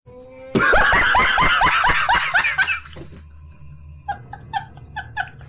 Sound Effects
Halloween Laugh